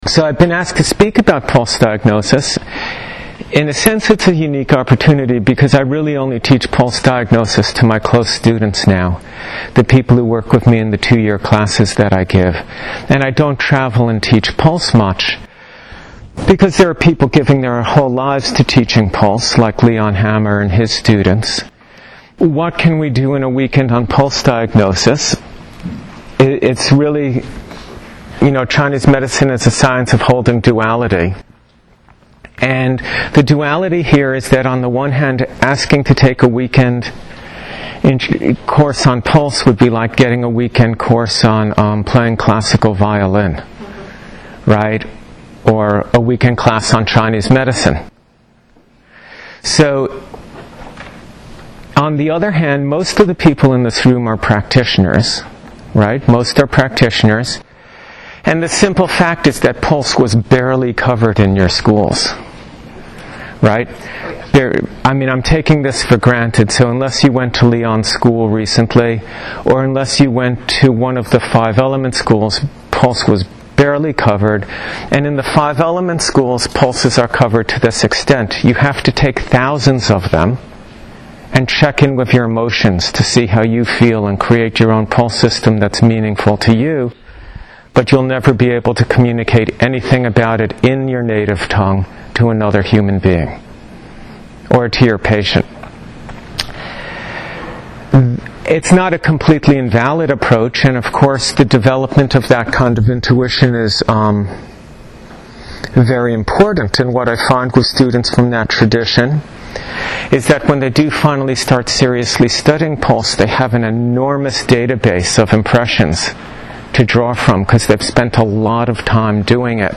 This is the first hour from my recent teaching for the Acupuncture Society in Vermont.